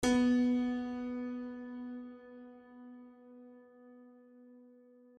piano-sounds-dev
b2.mp3